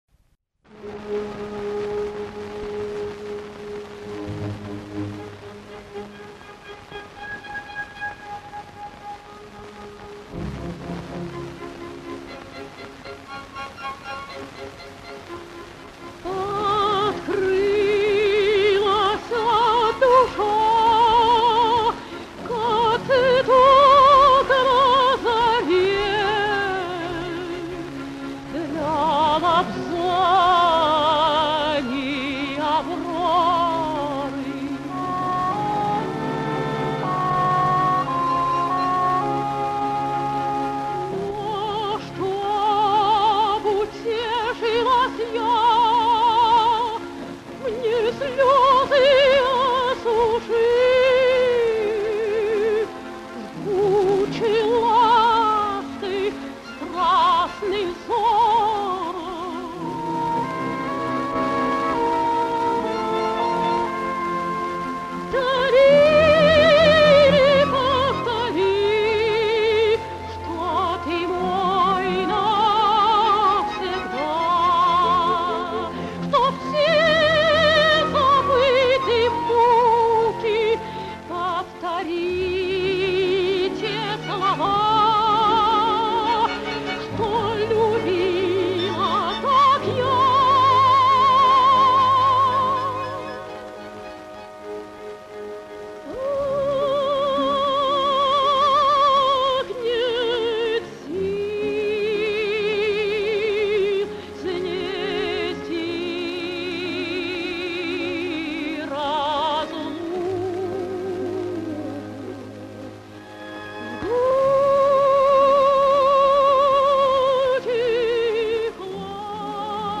Надежда Обухова (меццо-сопрано)